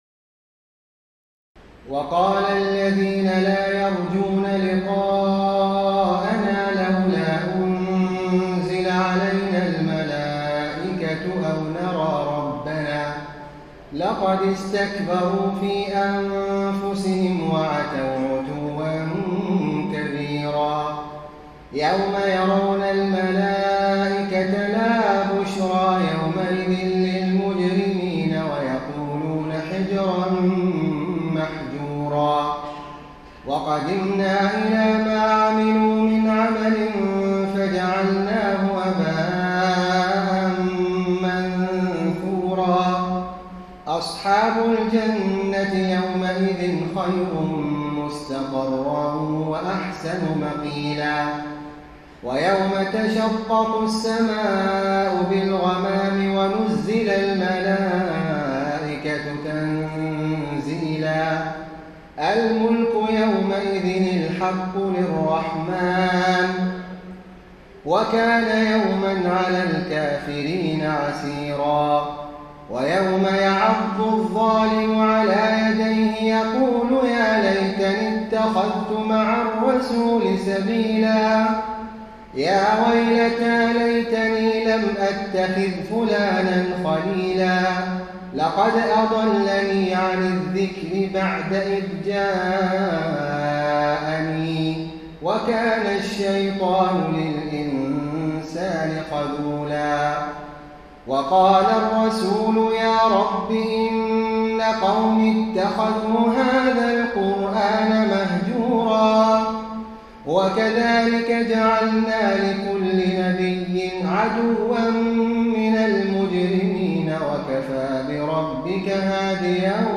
تراويح الليلة الثامنة عشر رمضان 1433هـ من سورتي الفرقان (21-77) و الشعراء (1-104) Taraweeh 18 st night Ramadan 1433H from Surah Al-Furqaan and Ash-Shu'araa > تراويح الحرم النبوي عام 1433 🕌 > التراويح - تلاوات الحرمين